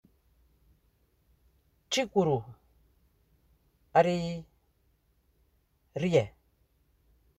Accueil > Prononciation > r > r